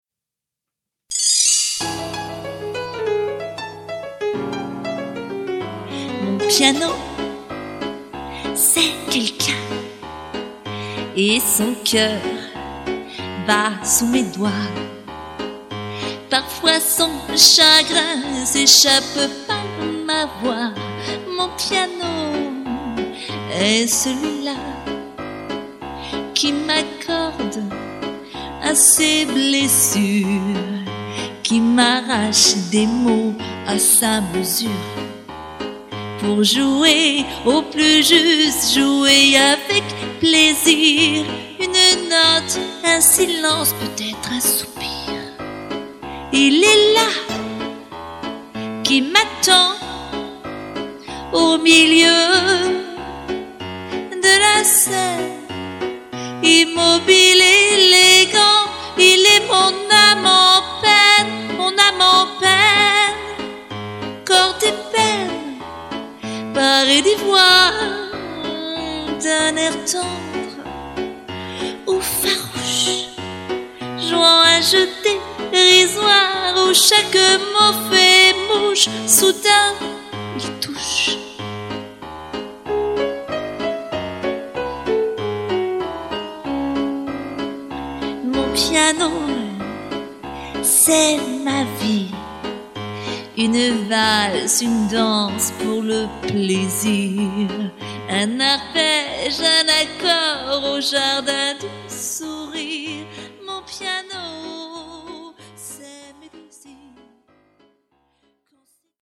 Intro piano solo
Fin solo piano